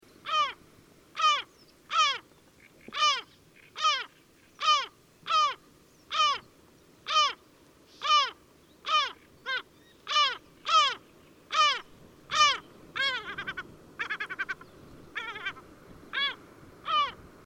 Large Gulls in the Southeastern Urals
Call recording 1